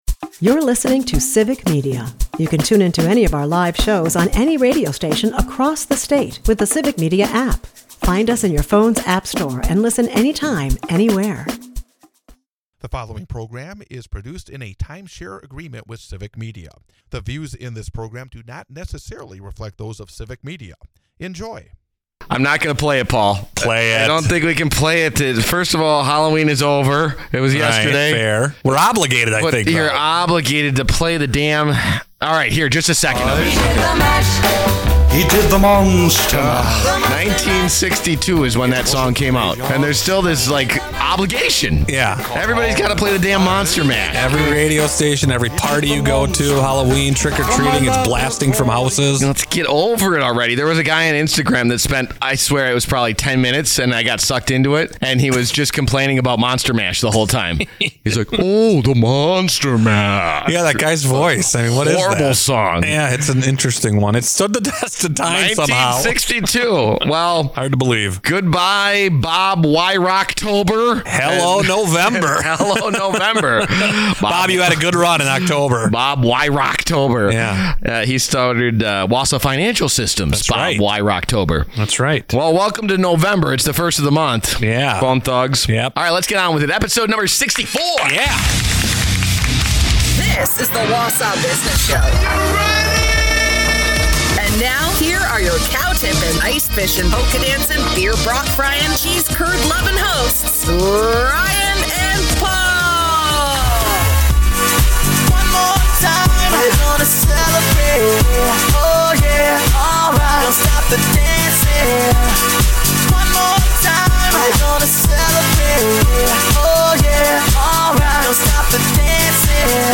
Plus $10,000 is up for grabs with The Friend of a Friend Competition Conclusion and... what's up with the HT Cobblery Building!? The Wausau Business Show is a part of the Civic Media radio network and airs Saturday from 8-9 am on WXCO in Wausau, WI.